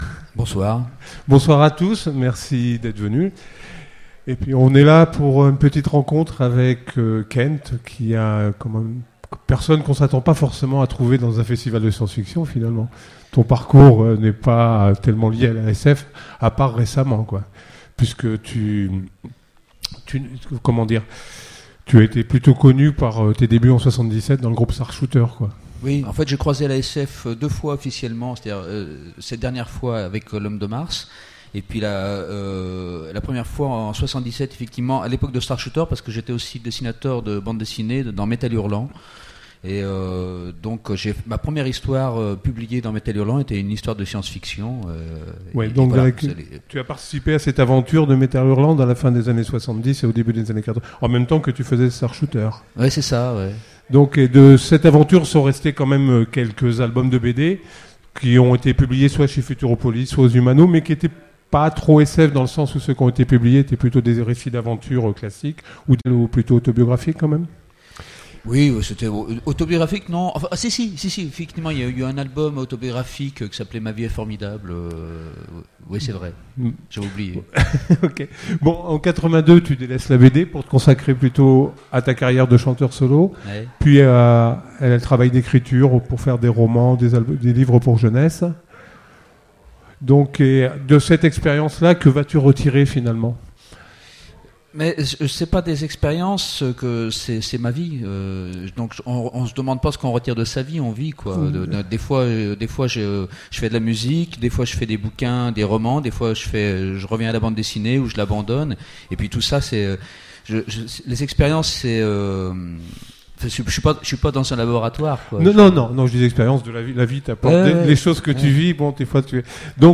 Voici l'enregistrement de la rencontre avec Kent aux Utopiales 2008 (les parties où il chante ont été supprimées).
Utopiales 2008 : Conférence - rencontre avec Kent Voici l'enregistrement de la rencontre avec Kent aux Utopiales 2008 (les parties où il chante ont été supprimées).